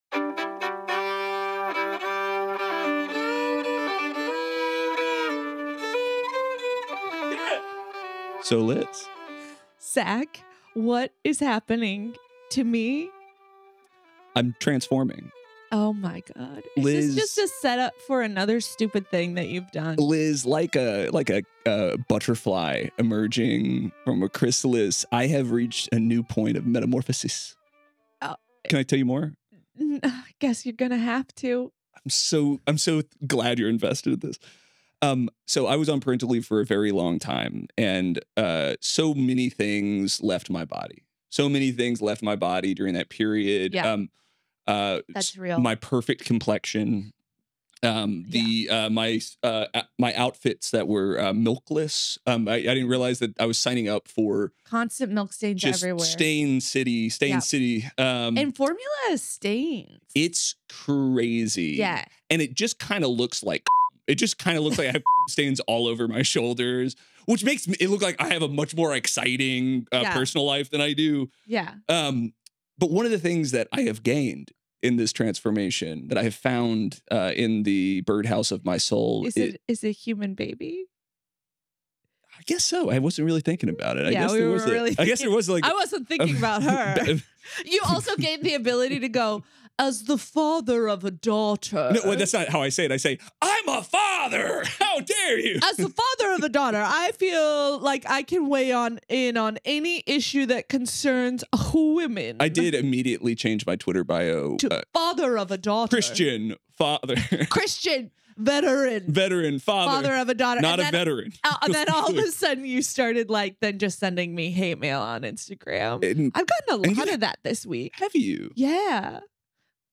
weekly comedy news podcast